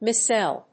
/maɪˈsɛl(米国英語)/